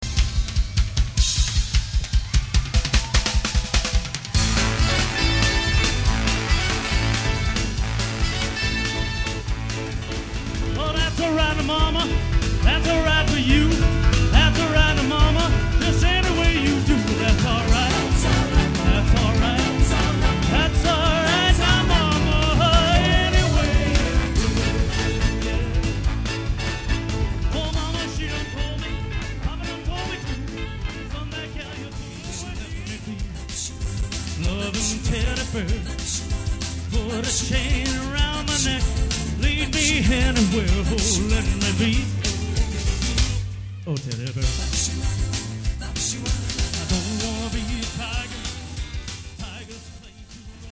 lead guitar
He also steps in on vocals to fill out the sound.